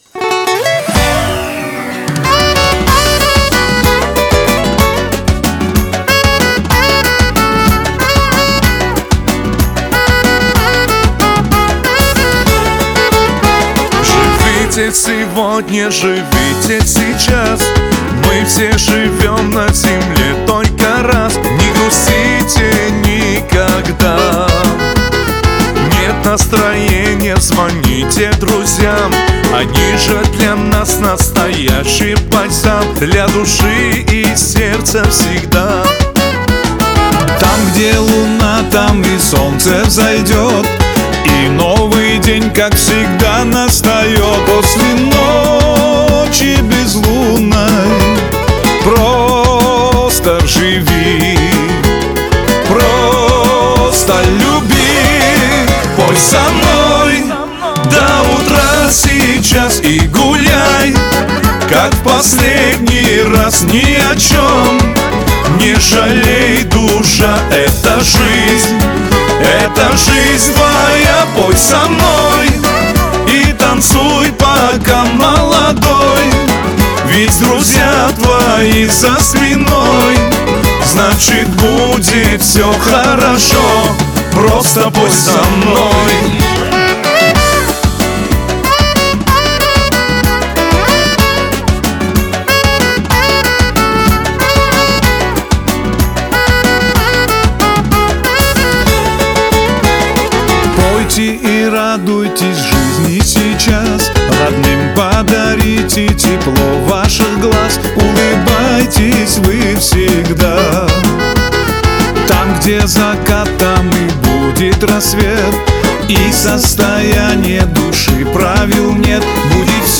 • Жанр: Поп, Русская музыка, Русский поп